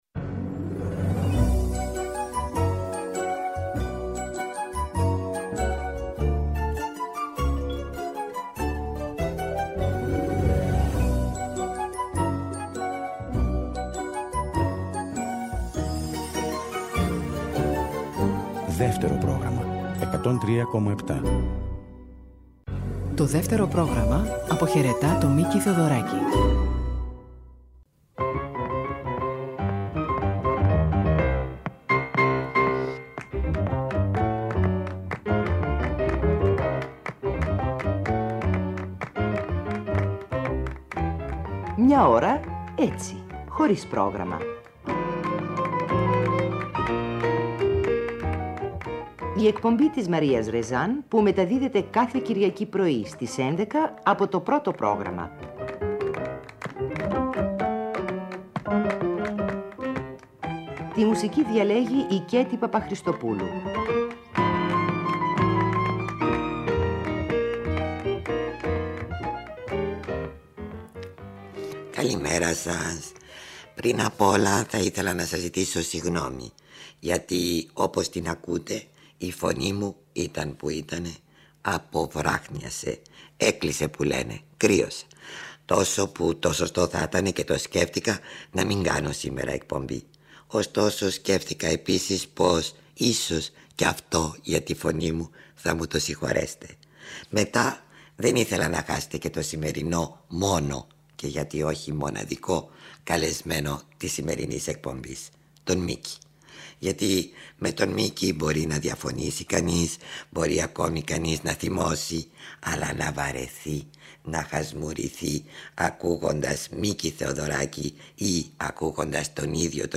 Από το αρχείο της Ελληνικής Ραδιοφωνίας ακούμε συνέντευξη του Μίκη Θεοδωράκη